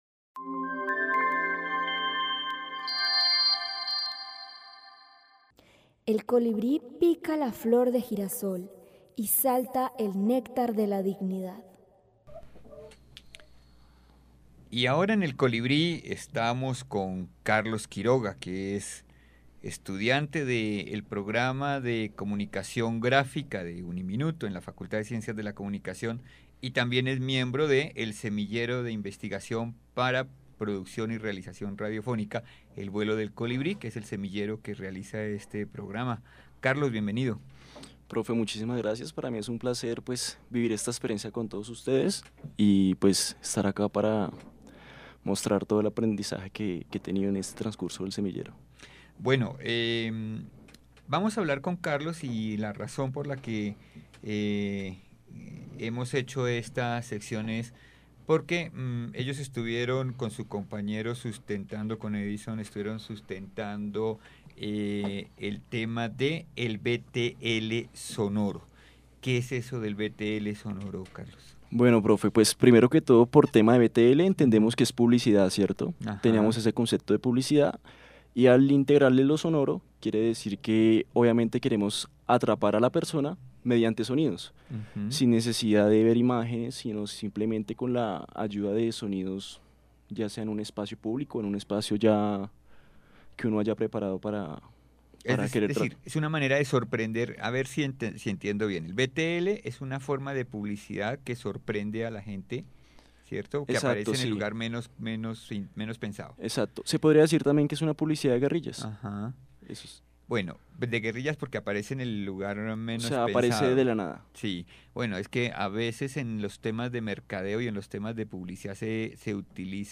Así que si quiere saber más sobre el tema, lo invitamos a escuchar la entrevista completa y conocer un poco más acerca de este interesante  proyecto.